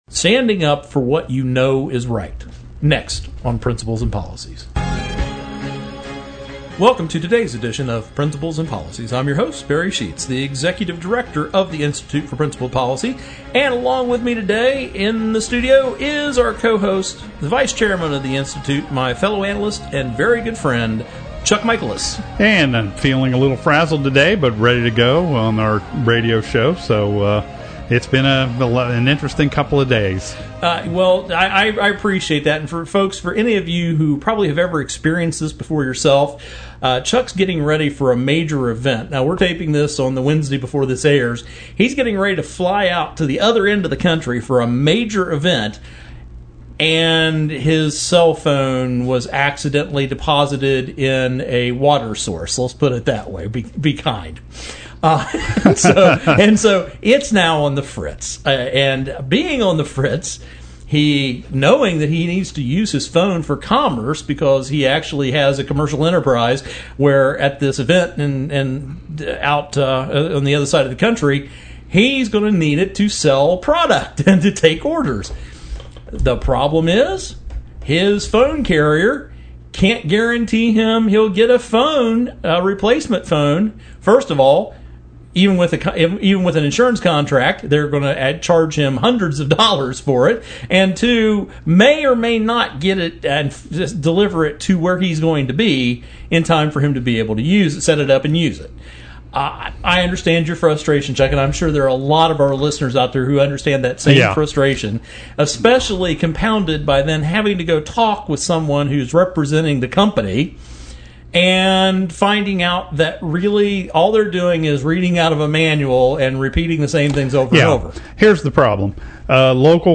Our Principles and Policies radio show for Saturday September 5, 2015.